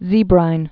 (zēbrīn)